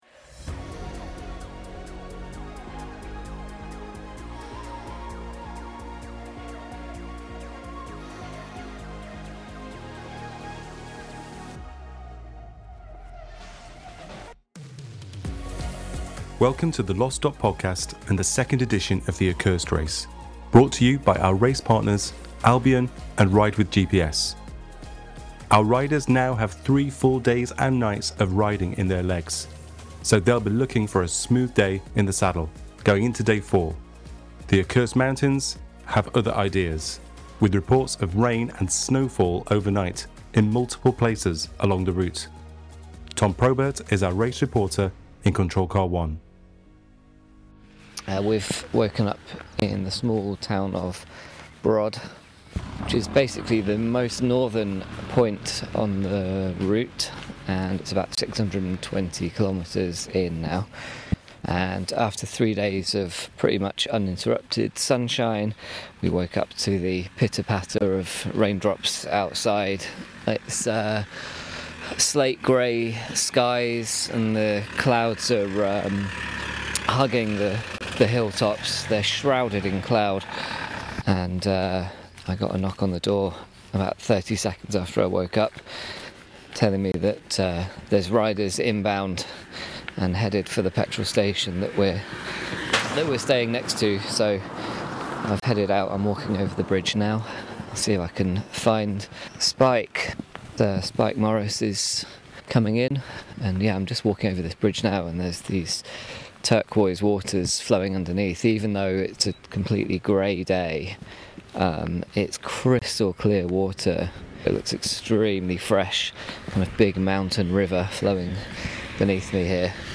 Listen to the tales and soundtracks of the race as the weather turns, adding a new layer of difficulty (and snow) to an already challenging course. As snow fell in the high mountains, it was a rainy and cold morning for the media team lower down, generally speaking to riders once they were de-frosting after descending from snowy peaks.